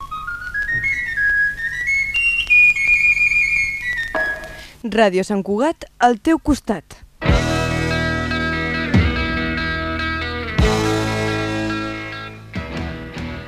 Indicatiu de l'emissora amb el lema "al teu costat".
FM